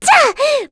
Luna-Vox_Attack6.wav